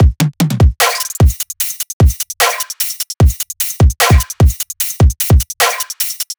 VR_drum_loop_dramaqueen_150.wav